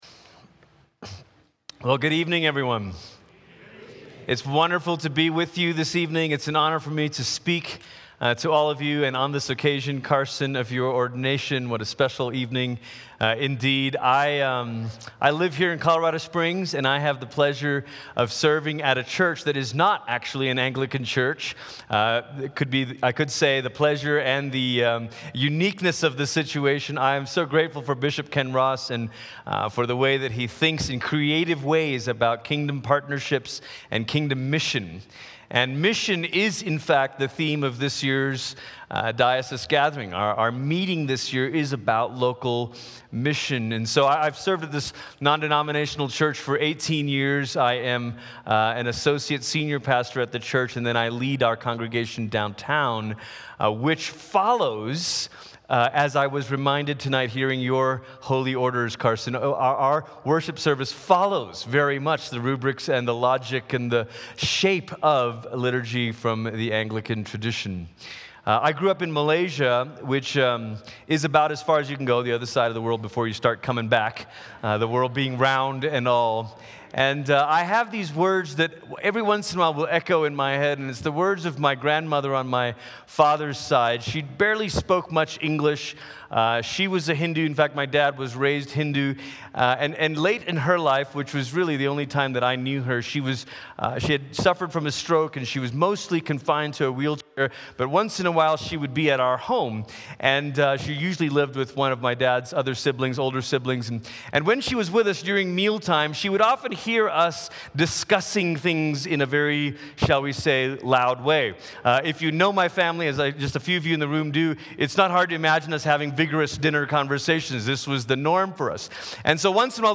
Gathering 2019 Opening Eucharist & Ordination